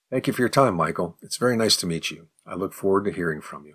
11_advanced_response_fast.mp3